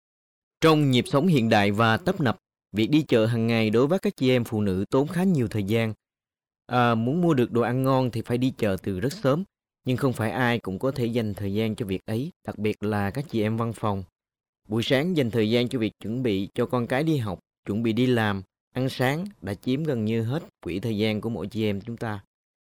Vietnamese  voice over